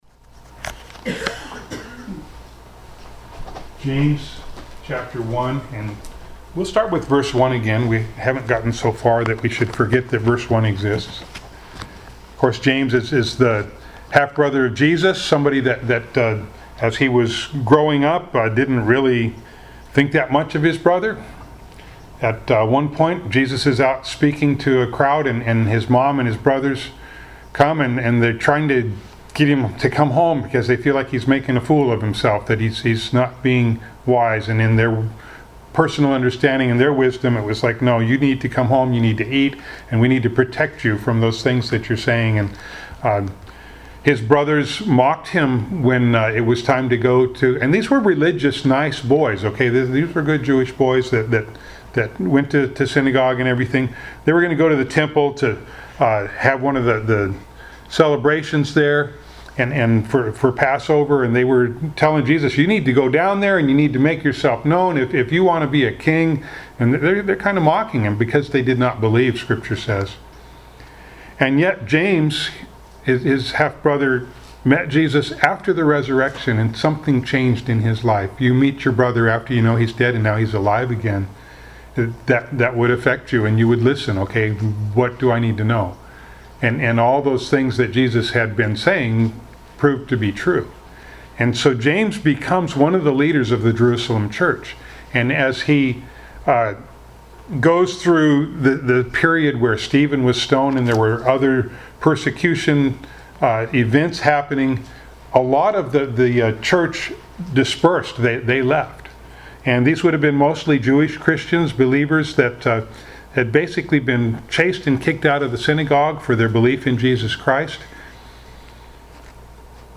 James 1:6-8 Service Type: Sunday Morning Bible Text